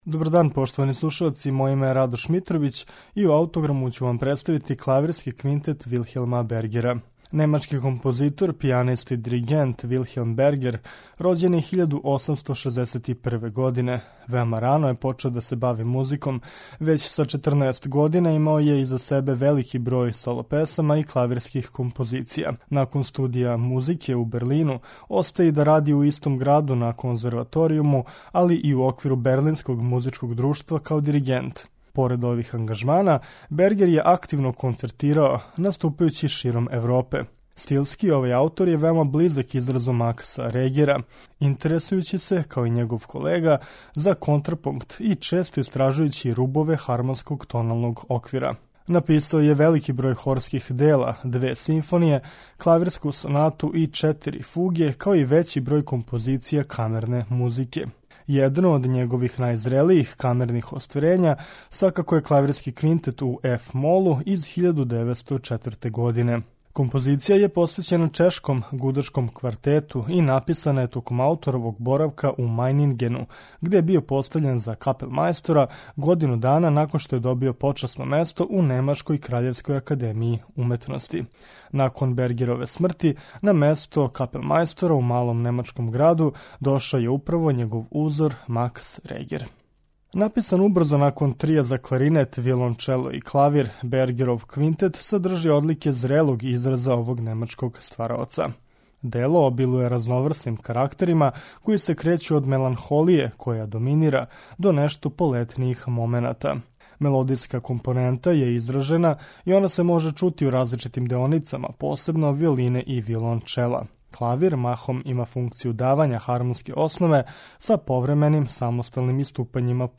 Представићемо Клавирски квинтет у еф-молу, Вилхелма Бергера